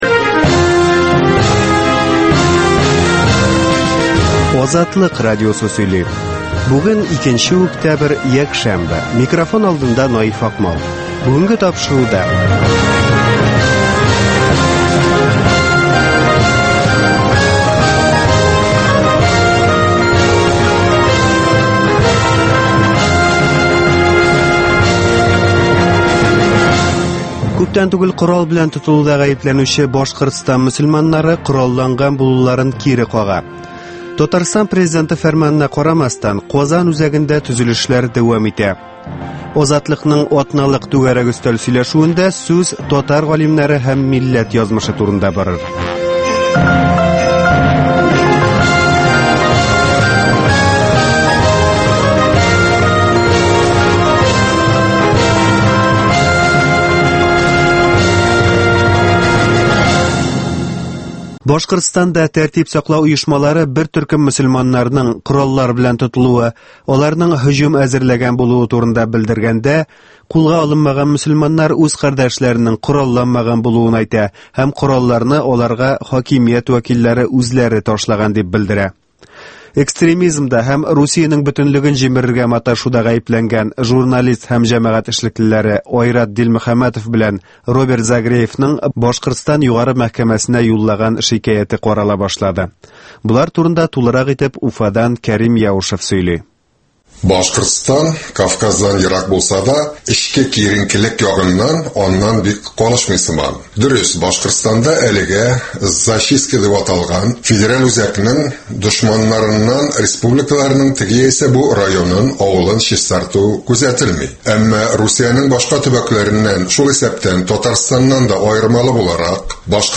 Азатлык узган атнага күз сала - Башкортстаннан атналык күзәтү - Татар дөньясы - Түгәрәк өстәл сөйләшүе - Коръәннән аятләр һәм аларның мәгънәсе